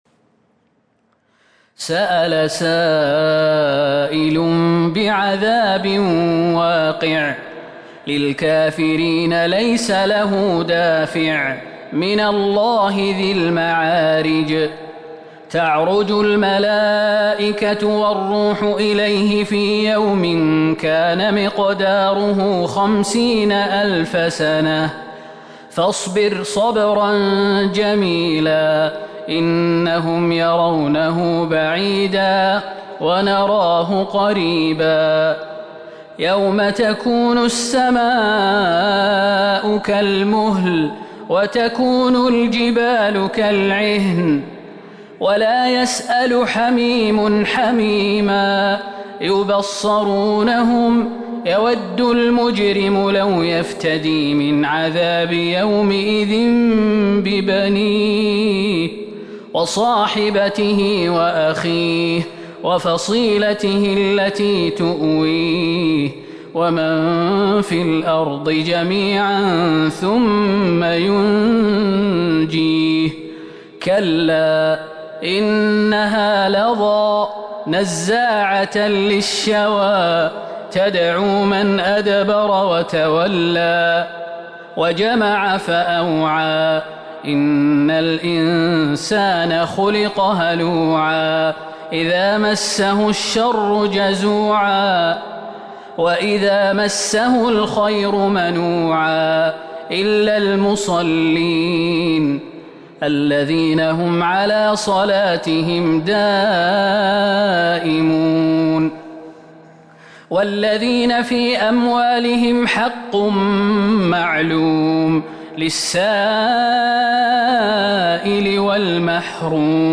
سورة المعارج Surat Al-Ma'arij من تراويح المسجد النبوي 1442هـ > مصحف تراويح الحرم النبوي عام ١٤٤٢ > المصحف - تلاوات الحرمين